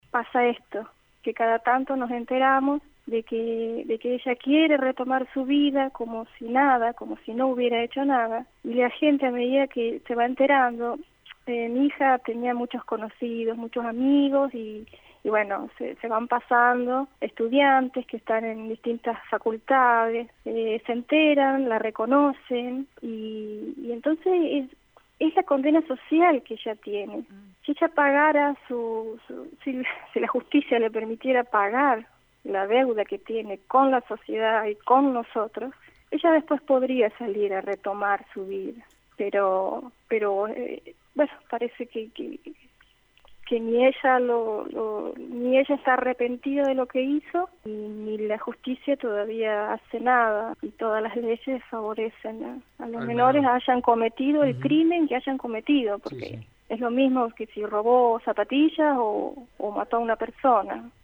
dialogó con Radio EME sobre la polémica en redes sociales.